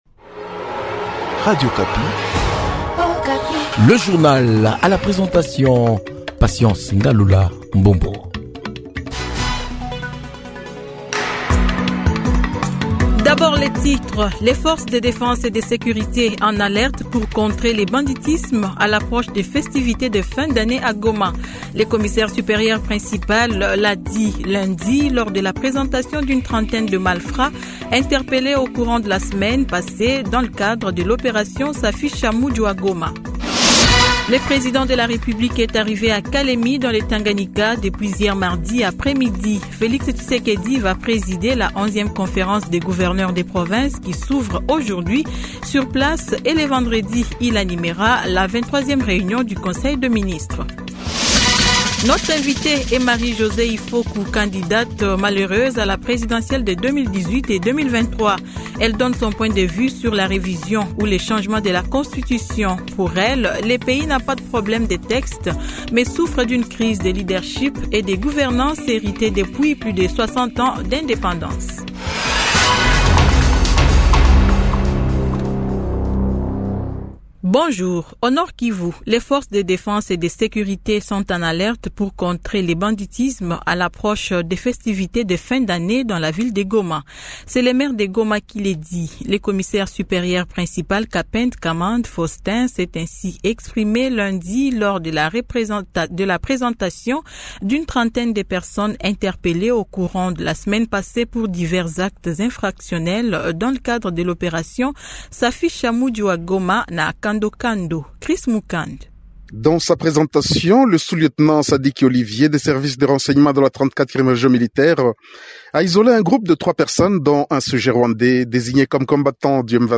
Journal matin 08H00